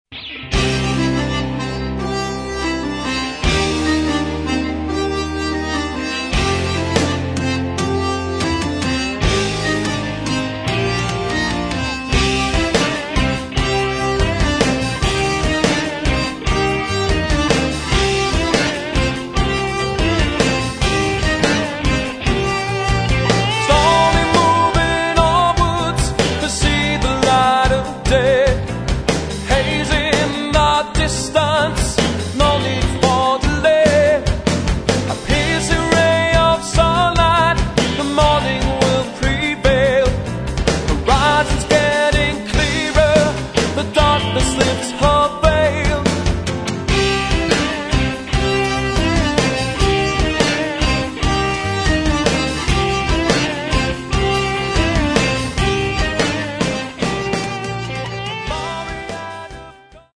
Metal
Очень нетрадиционный Progressive Metal.